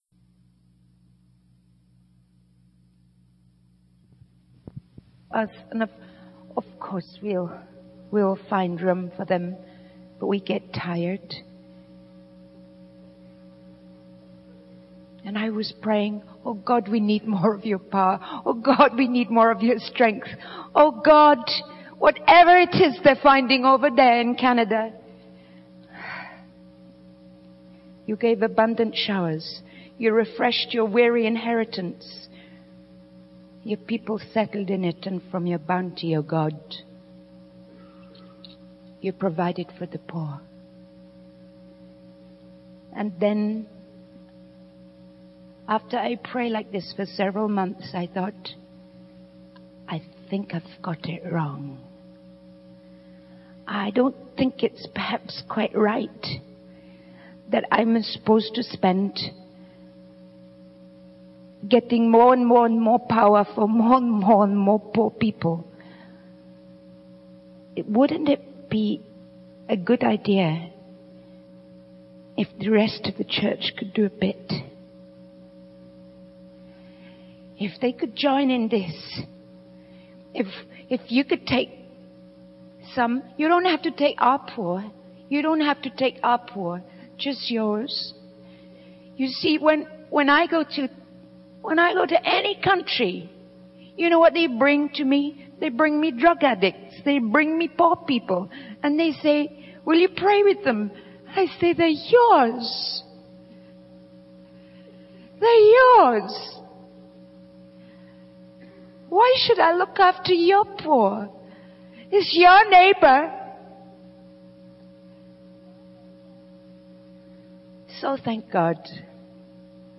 Please note that as these sermons were recorded on tape, some small segments may be missing.